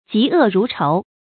注音：ㄐㄧˊ ㄨˋ ㄖㄨˊ ㄔㄡˊ
疾惡如仇的讀法